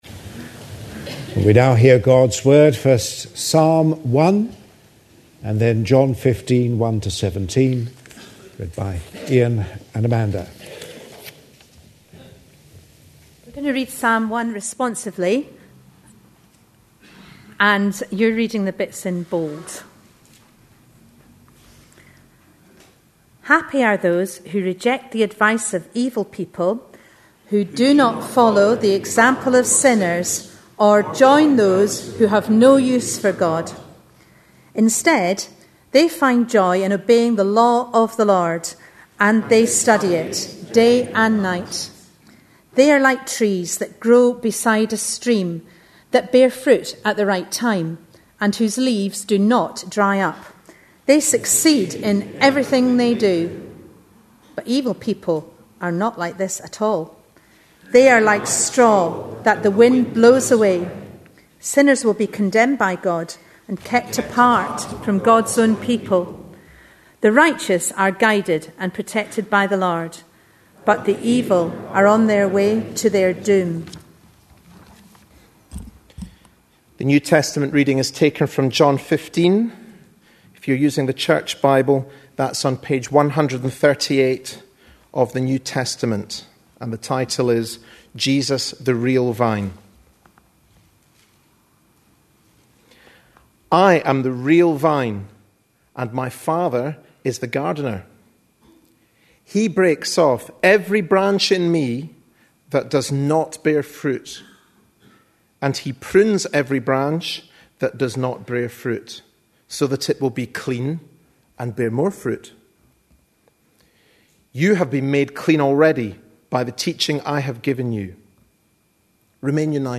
A sermon preached on 27th February, 2011, as part of our A Passion For.... series.
John 15:1-17 Listen online Read a transcript Details Readings are Psalm 1 (responsively) and John 15:1-17, with references to Mark 1:35 and Luke 6:12. (Some momentary interference on sound.)